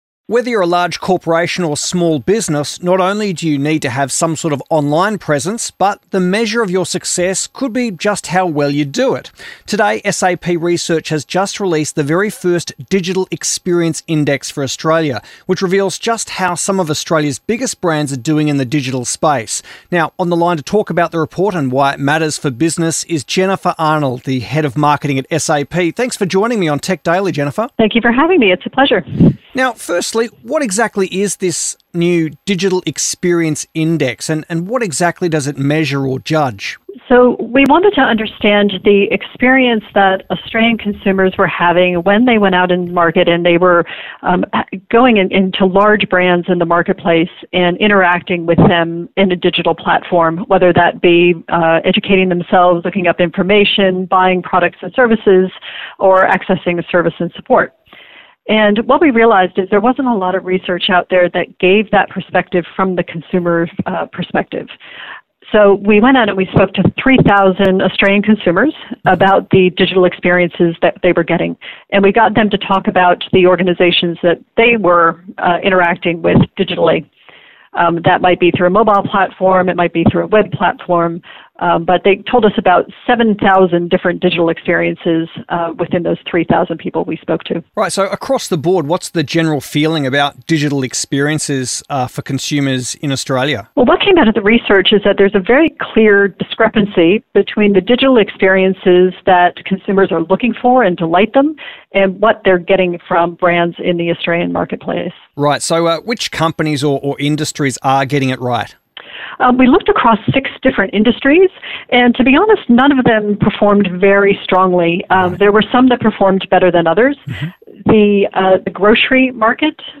Digital-Index-report_interivew.mp3